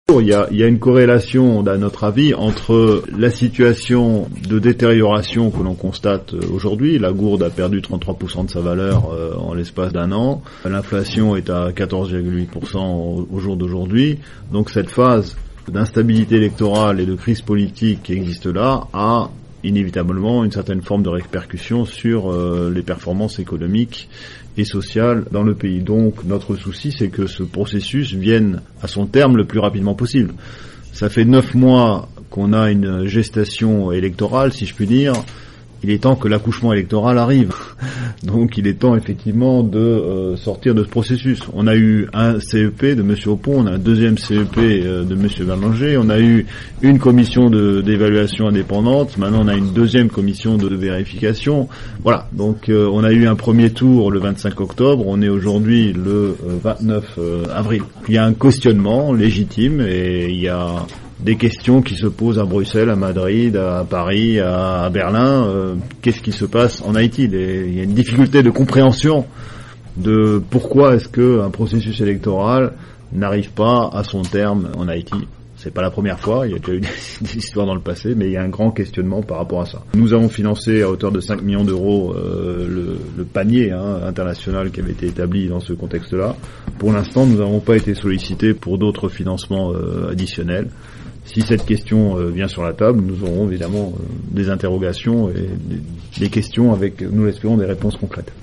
Anbasadè Inyon Ewopeyèn nan, Vincent Degert ka p pale sou pwosesis elektoral la ann Ayiti